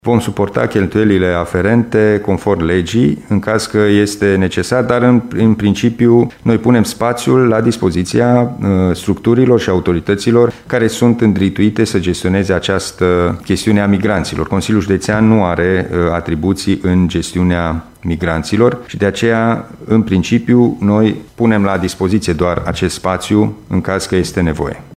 Spațiul va avea 47 de locuri, a spus președintele CJT, Alin Nica.